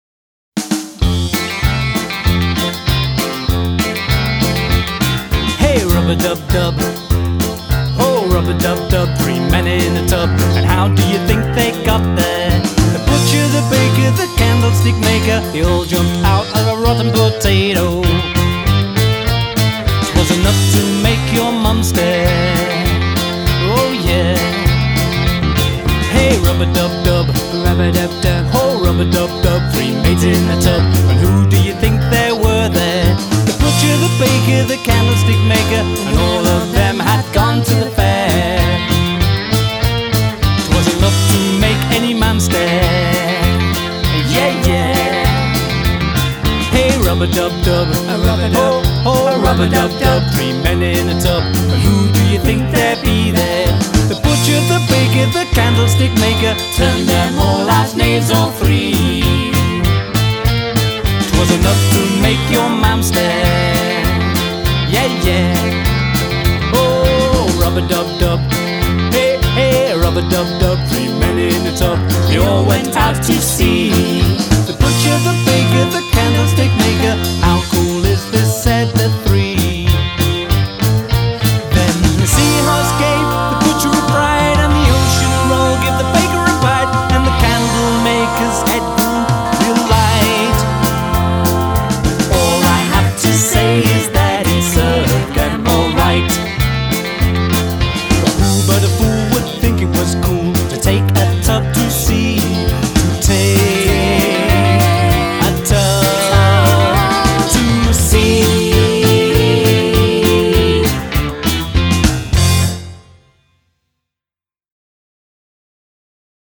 Fun & Punk